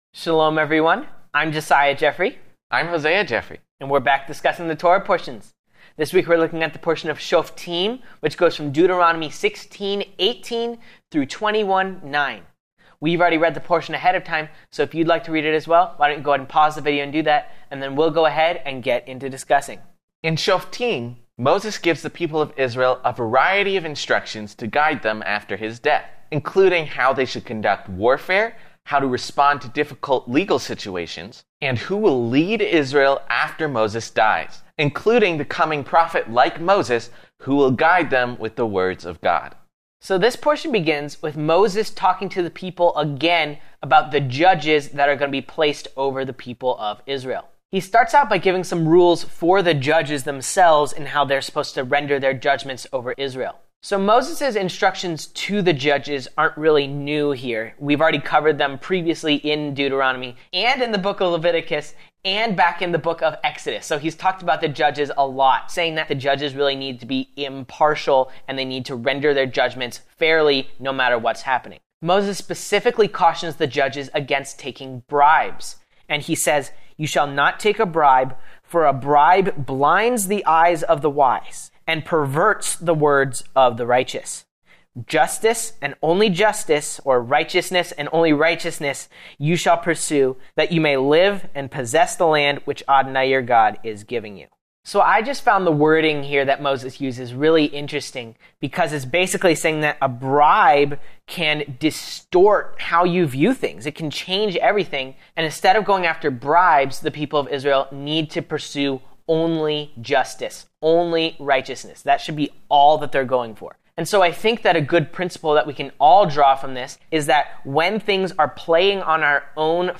In this week’s Messianic Jewish Bible study